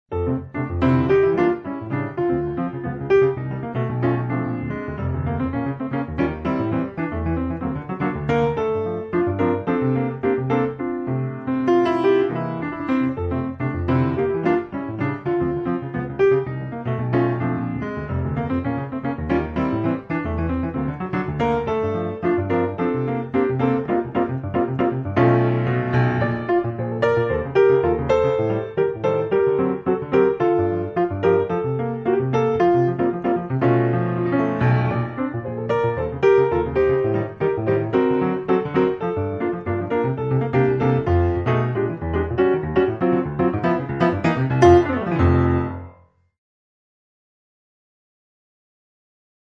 Sambossa.